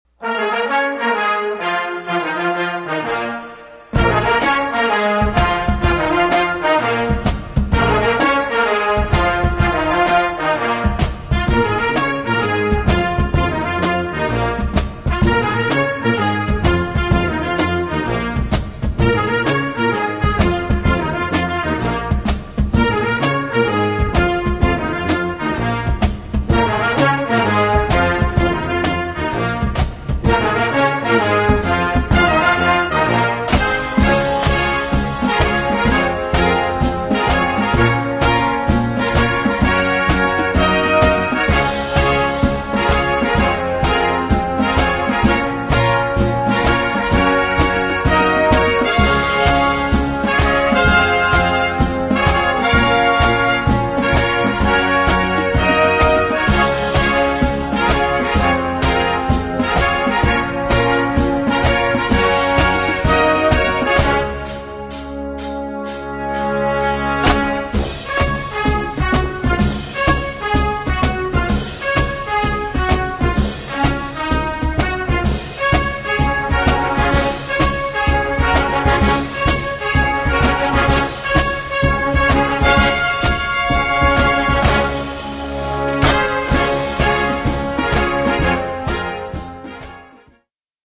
Gattung: Marching Band Series
Besetzung: Blasorchester
With an upbeat shuffle groove and catchy unison riffs